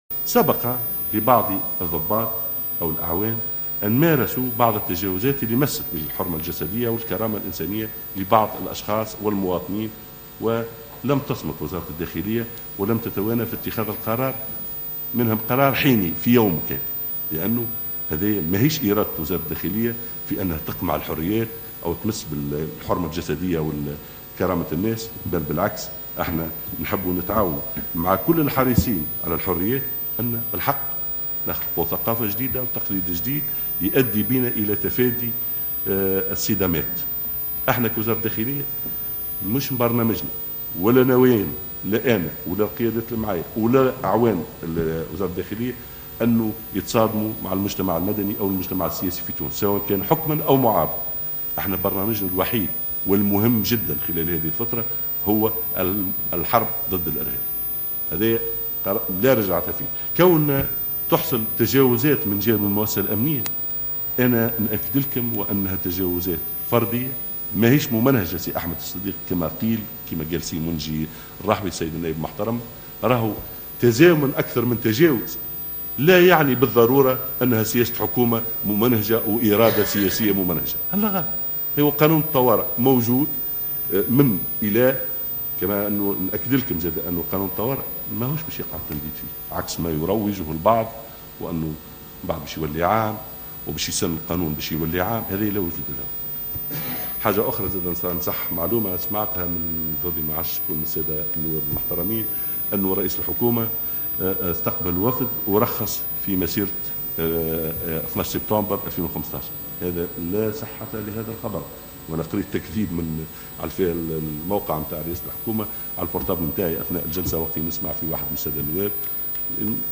ونفى الغرسلي خلال ندوة صحفية عقدها اثر جلسة الاستماع له بمجلس نواب الشعب وجود إرادة سياسية لقمع التحركات الاحتجاجية، مشيرا إلى أن منع التظاهر في هذه الفترة بالذات مردّه وجود تهديدات إرهابية قد تستهدف المتظاهرين نفسهم،وفق تعبيره.